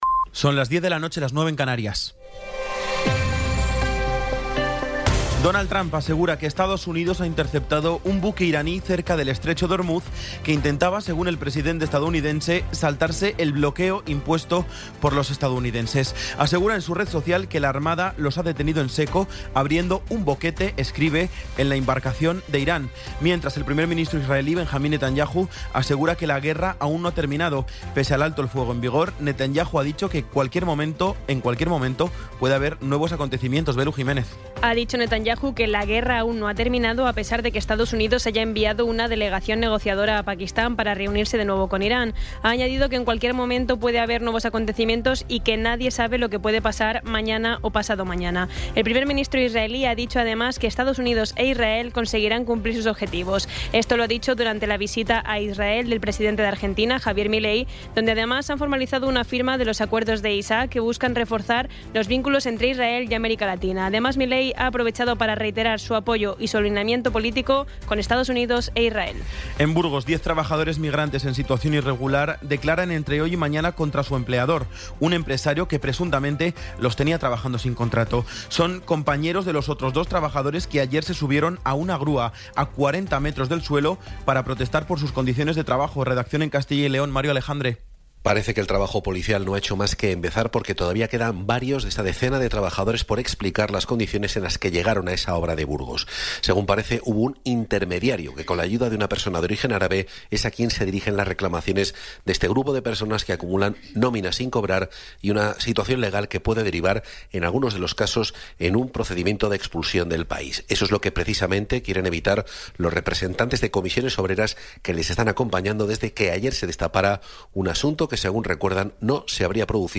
Resumen informativo con las noticias más destacadas del 19 de abril de 2026 a las diez de la noche.